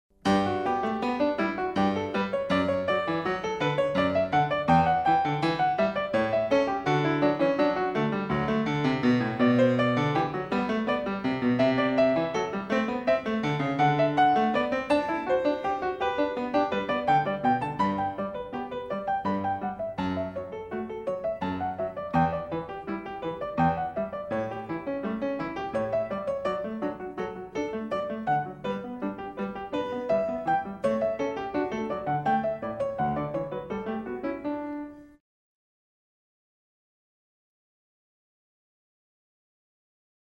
0223echo3a.mp3